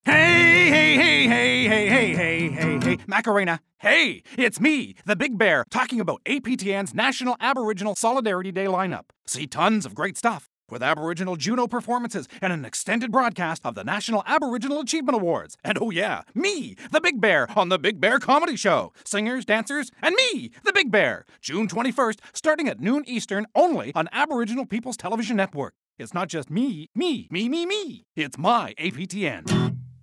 Voice Demo